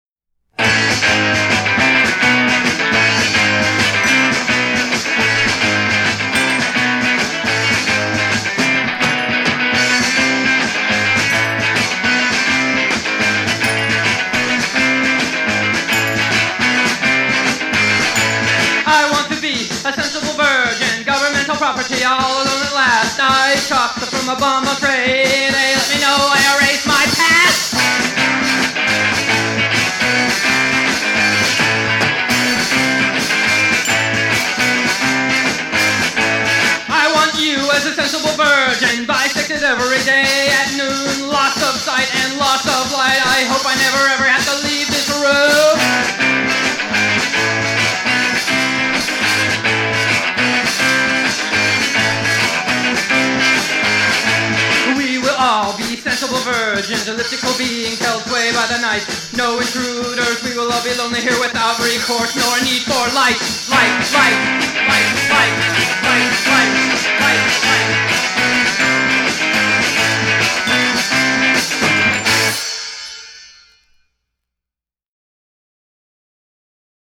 Guitar
Drums